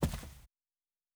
Footstep Carpet Walking 1_04.wav